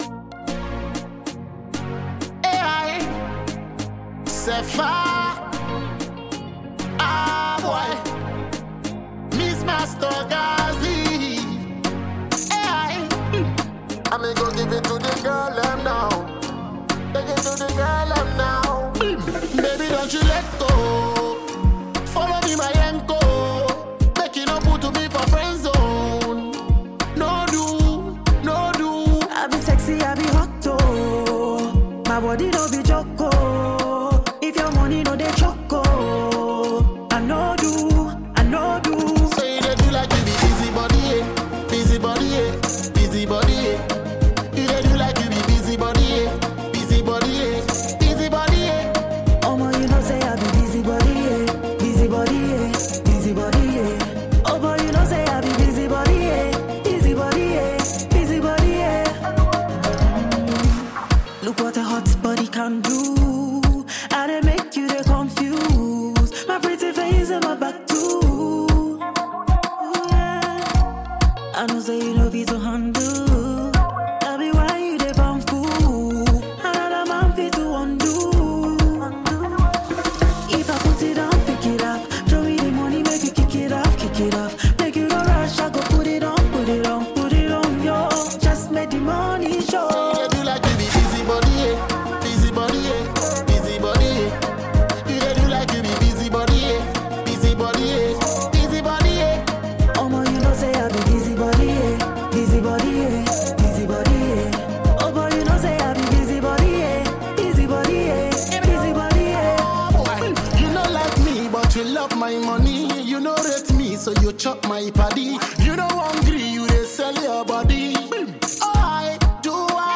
Ghana Music
Explosive New Club Anthem
high-energy Afrobeats record